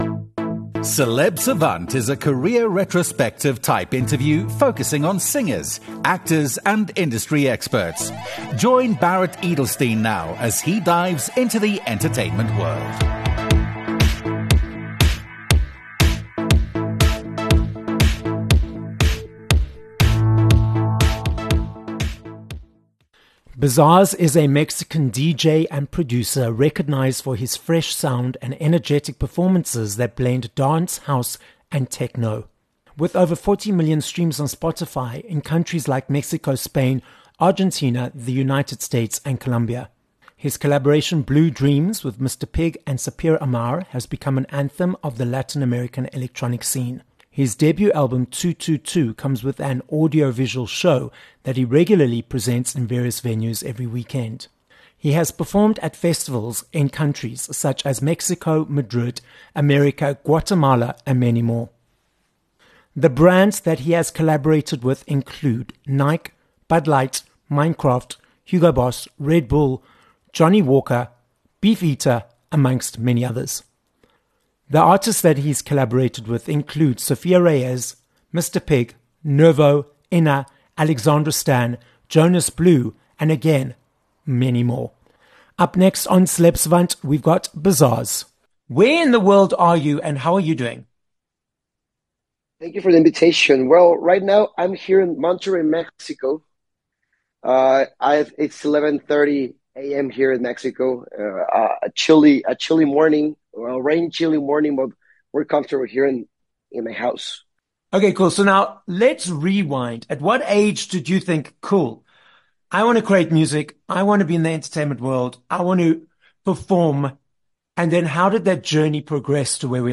31 Mar Interview